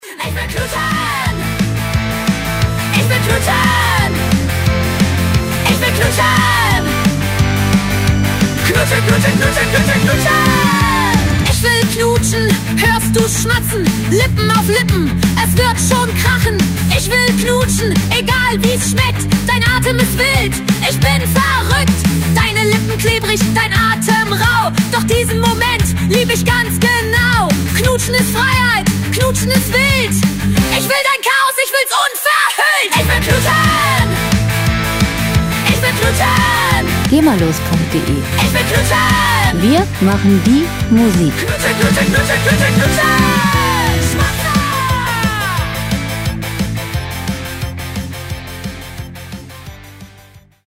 Pop Musik aus der Rubrik: "Popwelt Deutsch"
Musikstil: Deutschpunk
Tempo: 176 bpm
Tonart: A-Moll
Charakter: frech, kess